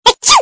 Чхи.....